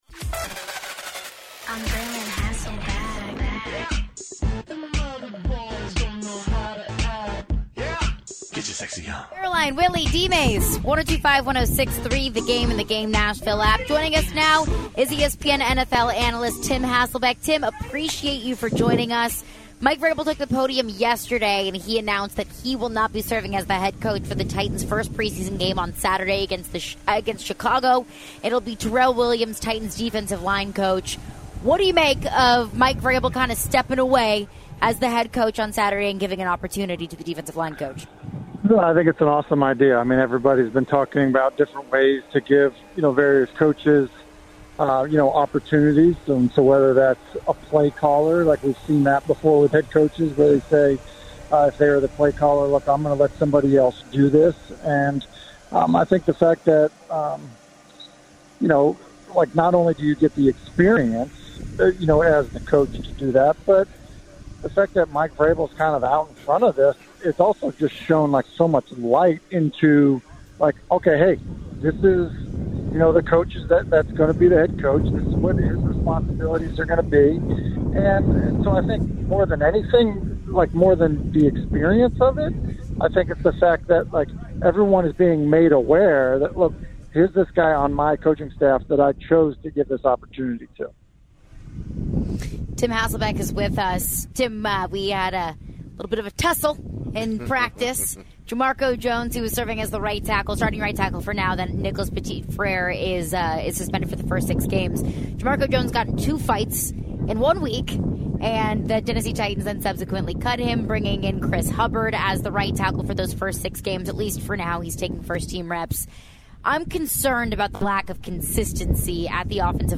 Later in the interview, Tim talks about the recent move head coach Mike Vrabel made with his coaching staff.